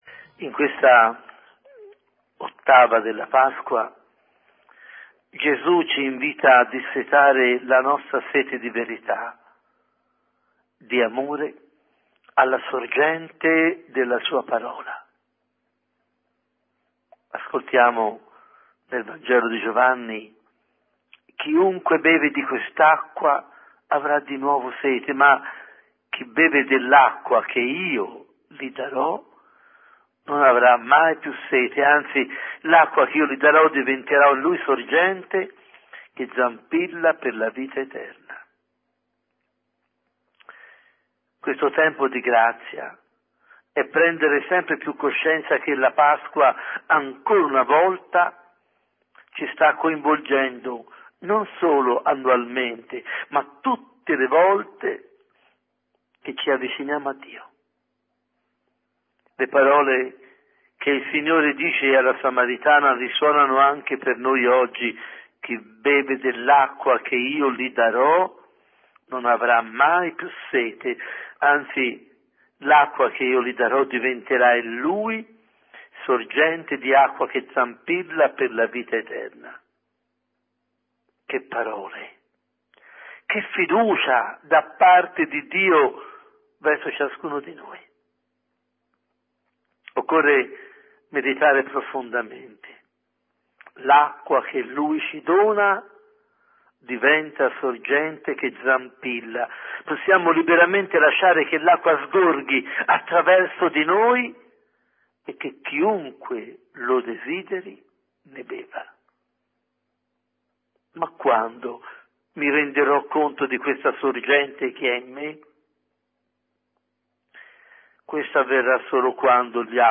Adorazione a Gesù eucaristico